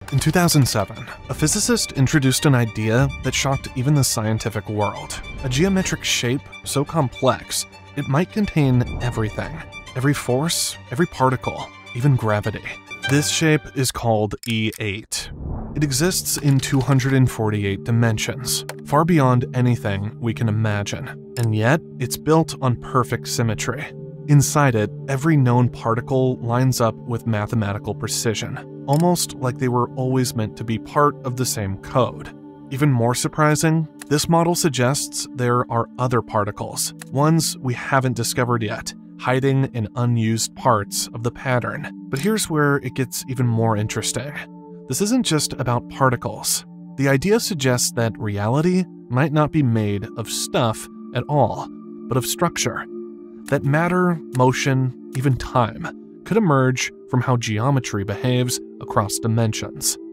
Yng Adult (18-29) | Adult (30-50)